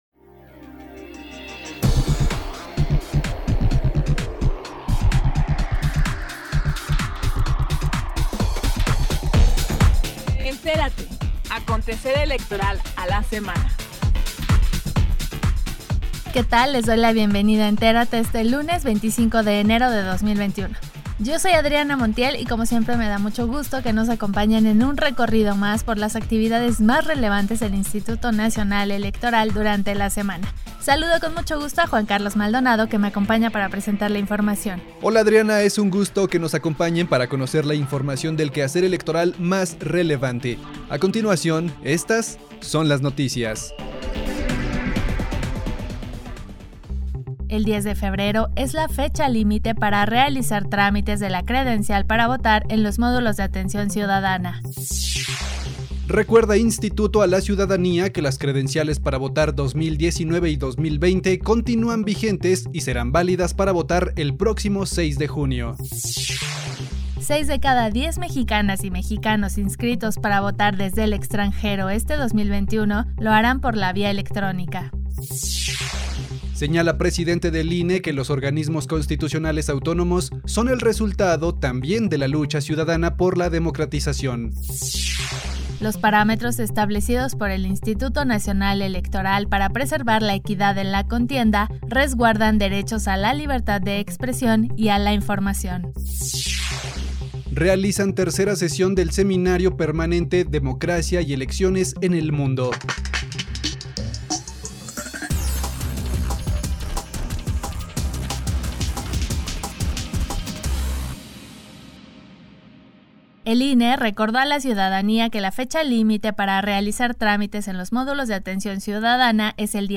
NOTICIARIO 25 DE ENERO 2021